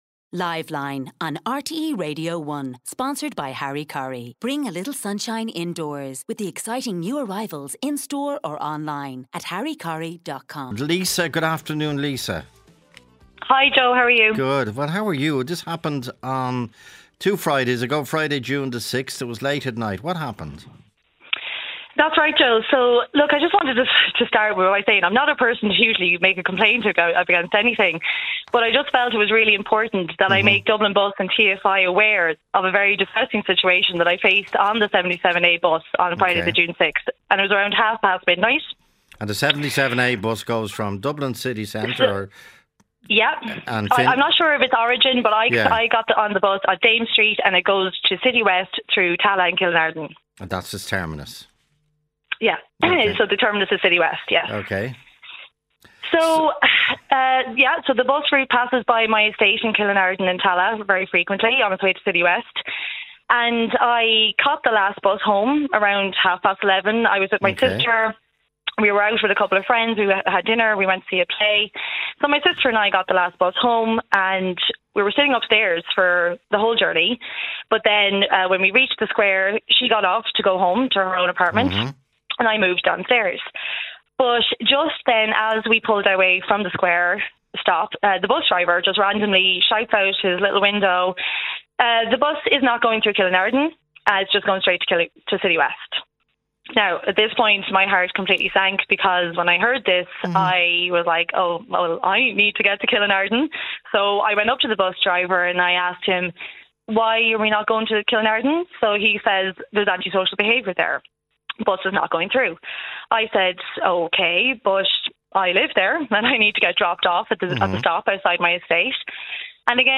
Joe Duffy talks to the Irish public about affairs of the day.
If you’d like to talk to Joe on-air about an issue that concerns you then we want to hear from you.